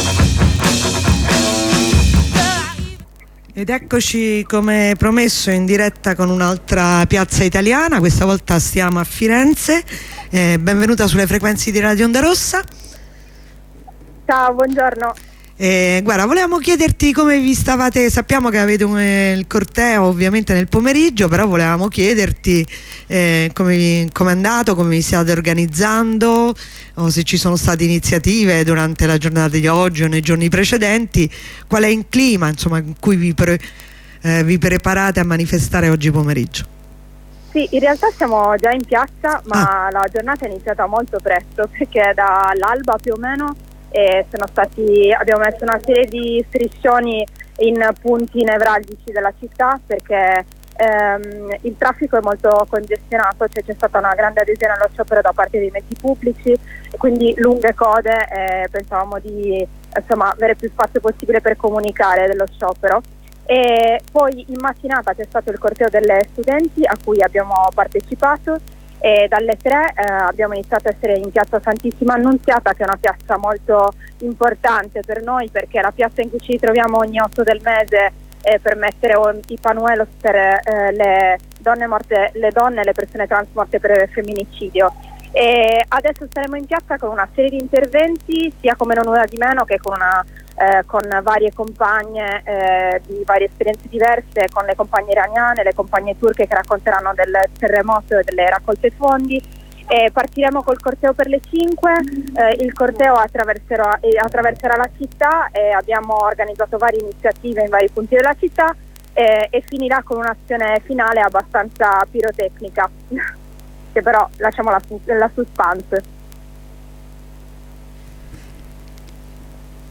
Una compagna di Non una di meno Firenze racconta da piazza Santissima Annunziata dove è in atto (ore 16) il concentramento del corteo dell'8 marzo, racconta il corteo studentesco della mattina e le altre iniziative preparatorie del corteo transfemminista del pomeriggio.